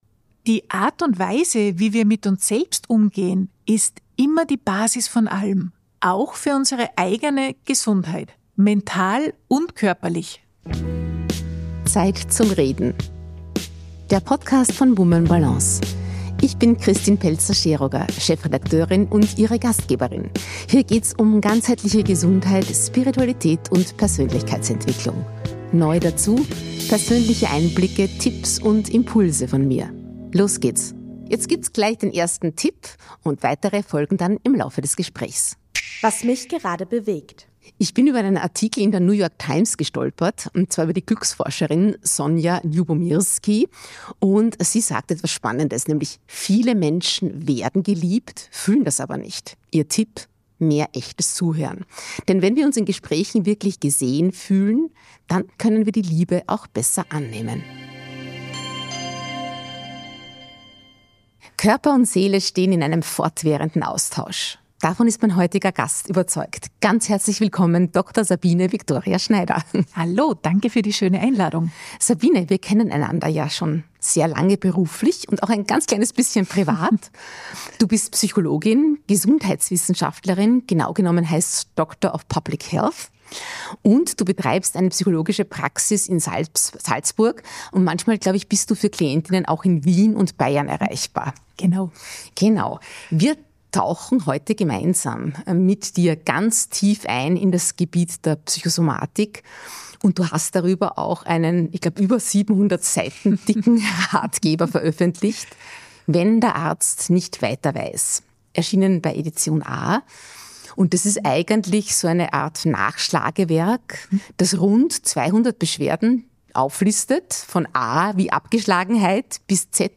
Ein Gespräch über die Chancen – und auch die Grenzen – der psychosomatischen Medizin.